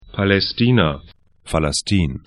Aussprache
Palästina palɛ'sti:na Falaştīn falas'ti:n ar Gebiet / region 32°00N, 35°15'E